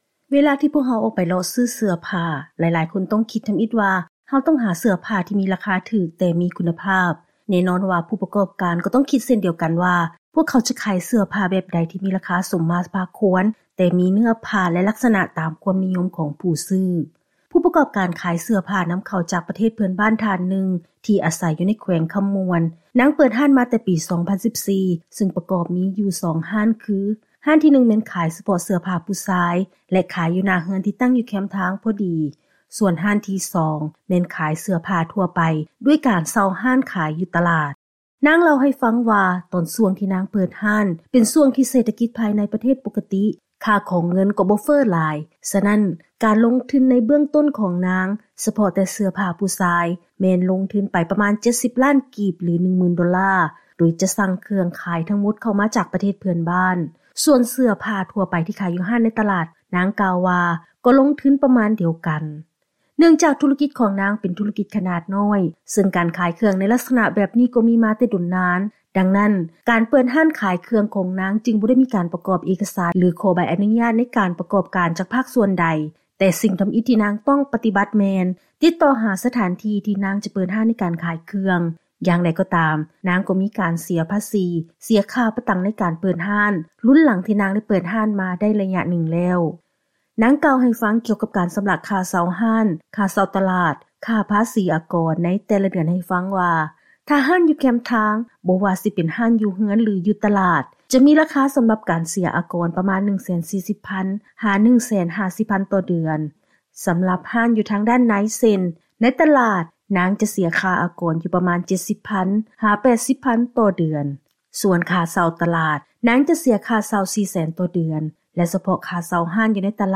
ເຊີນຟັງລາຍງານກ່ຽວກັບ ການດໍາເນີນທຸລະກິດຂະໜາດນ້ອຍ ສໍາລັບຂາຍເສື້ອຜ້ານໍາເຂົ້າ.